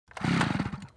Interact_WULA_MW_ChainSword.wav